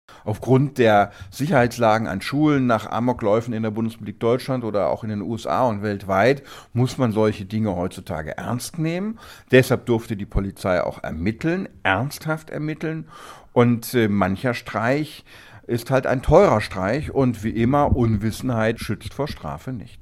O-Ton + Kollegengespräch: Anonyme Drohung bei Instagram – Schüler muss Polizeikosten tragen